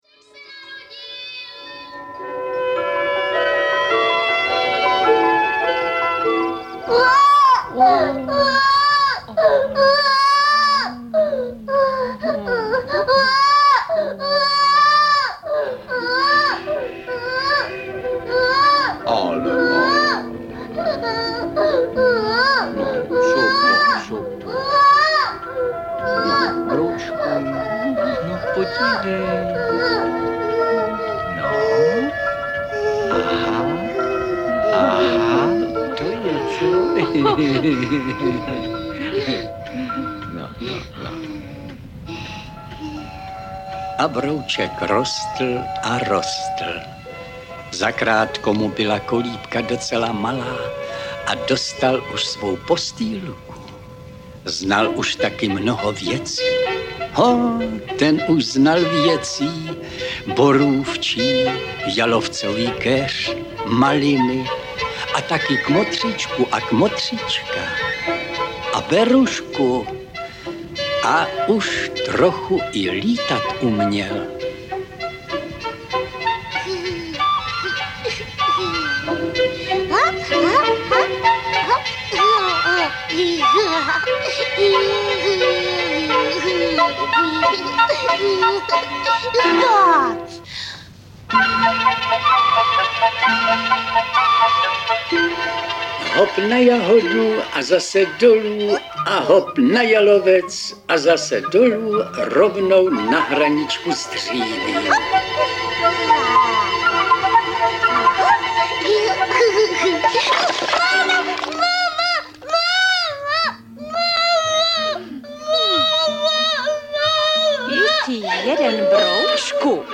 Broučci (verze II) audiokniha
Ukázka z knihy